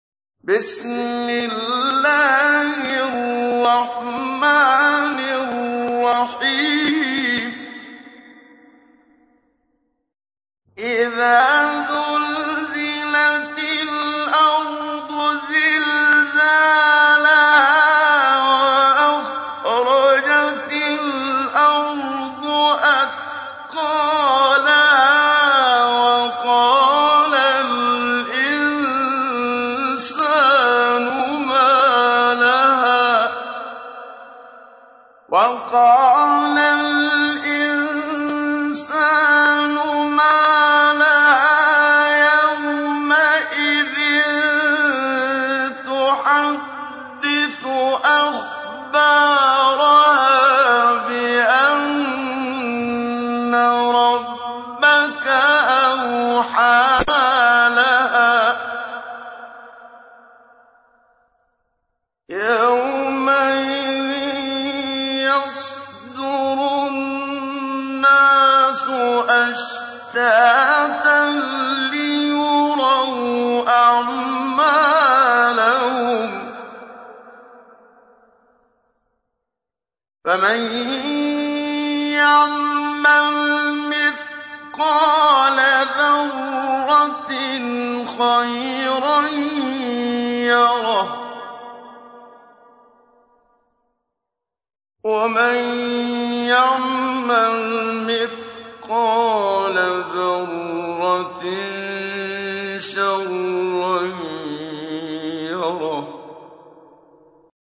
تحميل : 99. سورة الزلزلة / القارئ محمد صديق المنشاوي / القرآن الكريم / موقع يا حسين